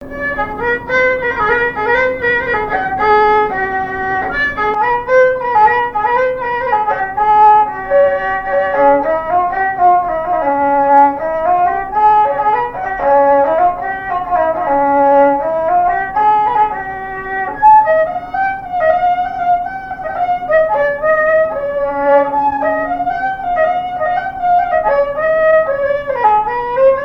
Beauvoir-sur-Mer
Couplets à danser
branle : courante, maraîchine
répertoire au violon et à la mandoline
Pièce musicale inédite